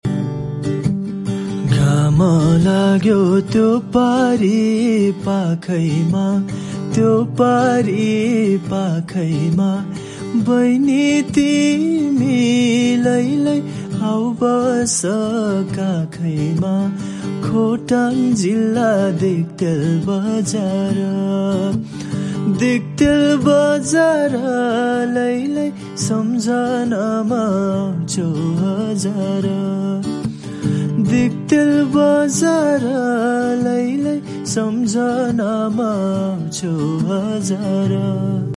raw cover song